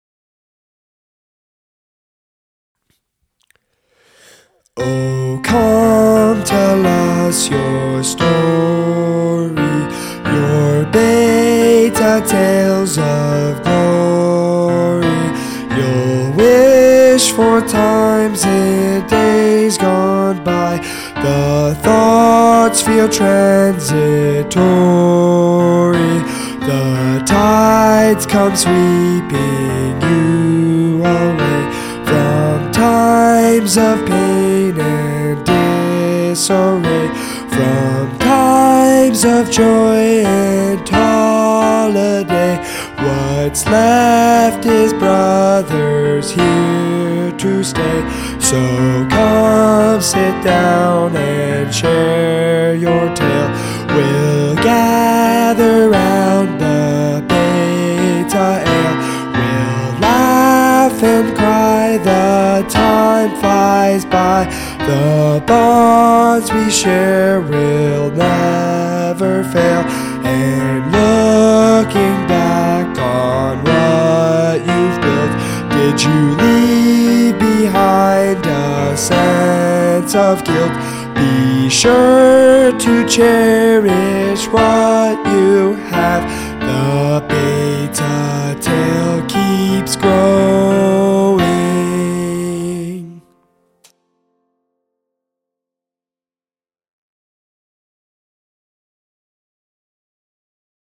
Singing Awards